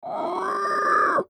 AV_pig_howl.mp3